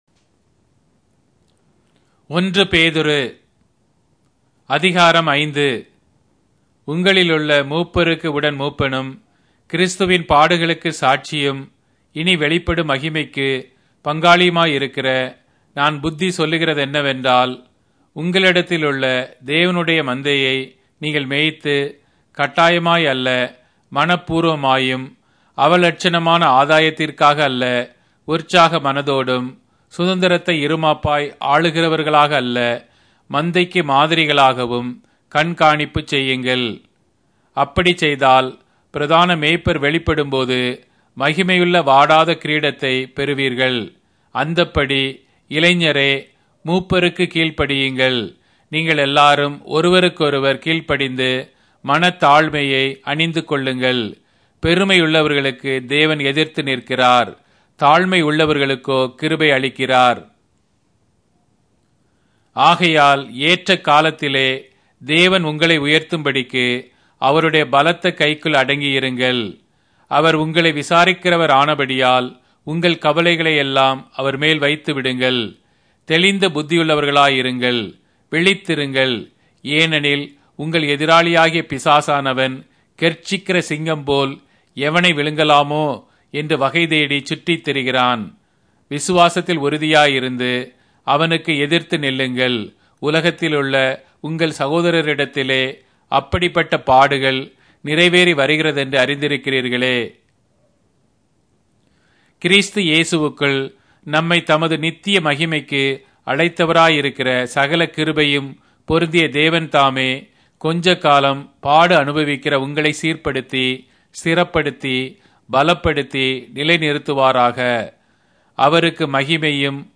Tamil Audio Bible - 1-Peter 3 in Ncv bible version